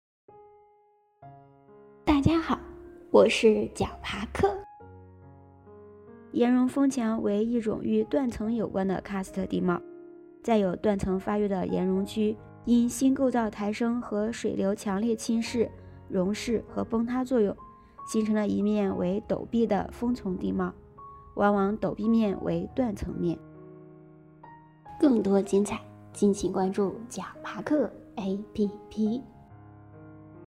岩溶峰墙----- 豌豆荚 解说词: 岩溶峰墙为一种与断层有关的喀斯特地貌，在有断层发育的岩溶区，因新构造抬升和水流强烈侵蚀、溶蚀和崩塌作用，形成了一面为陡壁的峰丛地貌，往往陡壁面为断层面。